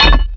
metalHeavyOnStone_start.WAV